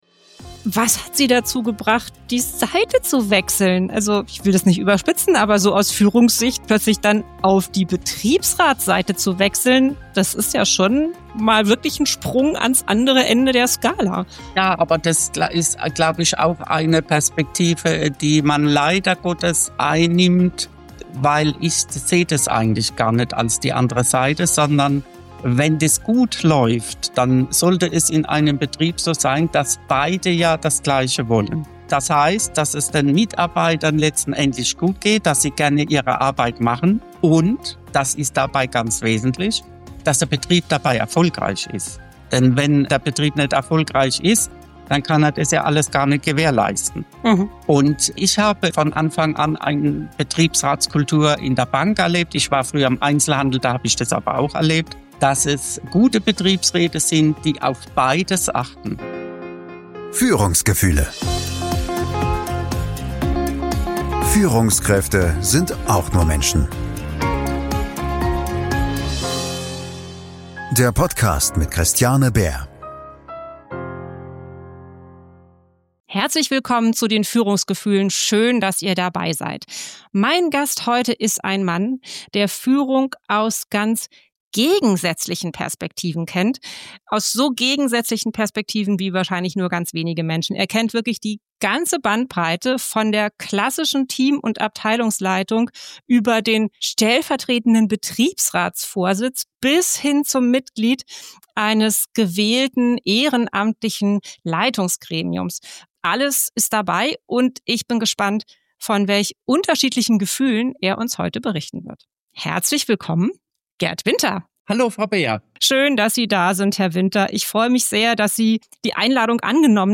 Diese Folge ist ein eindrucksvolles Gespräch über Menschlichkeit, Klarheit und die Kunst, das gemeinsame Interesse nie aus dem Blick zu verlieren.